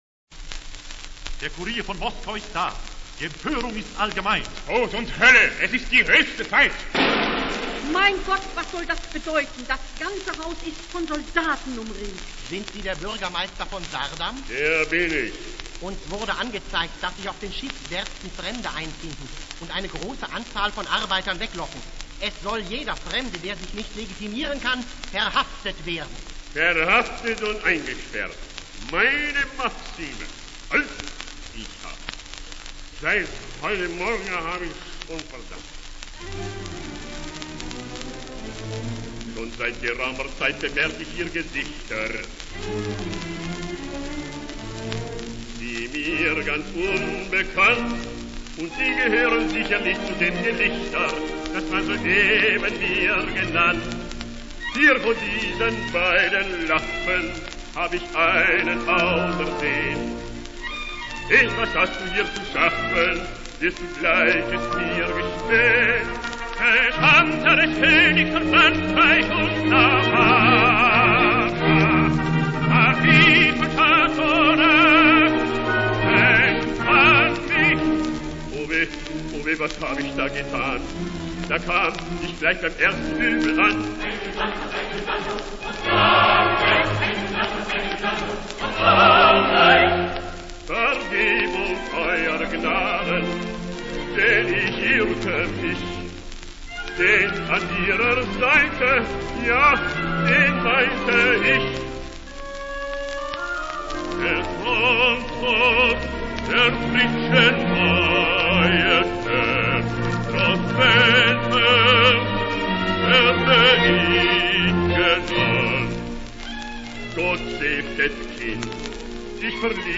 Eine komplette Oper !
Zar und Zimmermann als Kurzoper auf Schellack.
In der folgenden Hörprobe ist eine komplette Oper zu hören, verteilt auf vier 30 cm Platten.
Peter der 1. Zar von Rußland - Willy Domgraf-Faßbaender
Marie - Tilly de Garmo